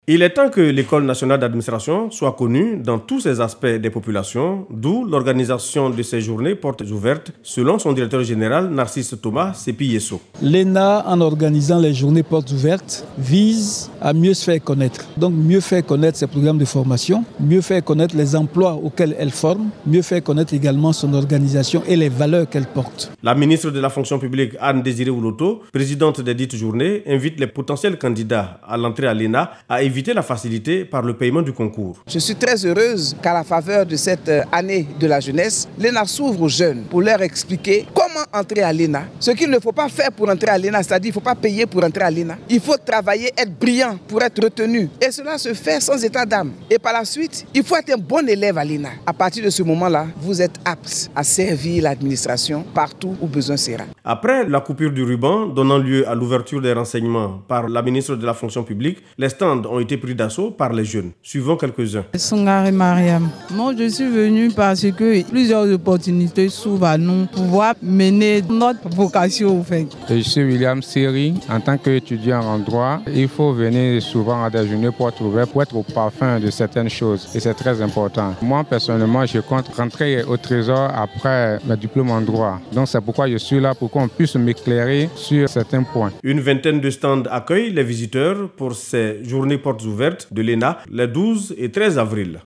L’Ecole Nationale d’Administration s’ouvre à la population. Elle organise ses journées portes les 12 et 13 avril 2021 au sein de l’Etablissement à Abidjan 2 Plateaux.